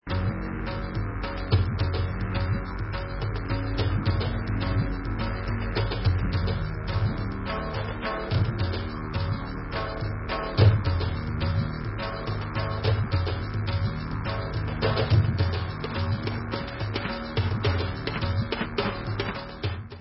Genre: Progressive Rock